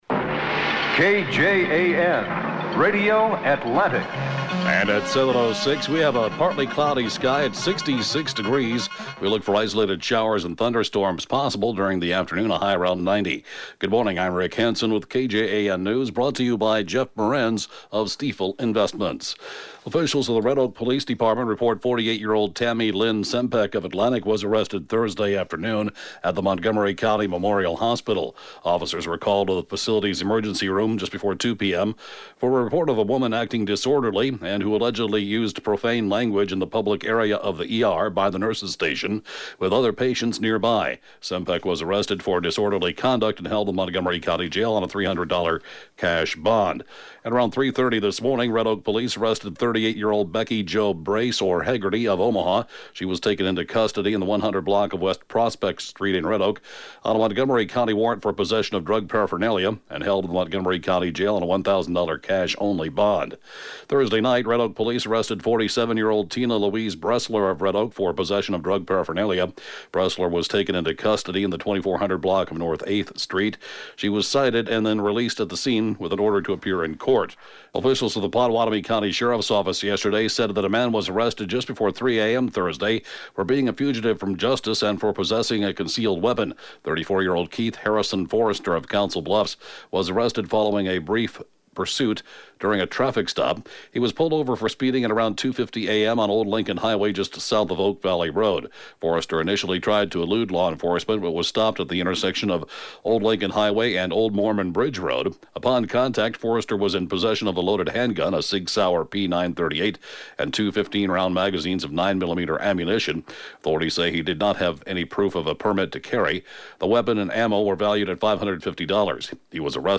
(Podcast) KJAN Morning News & funeral report, 12/29/2016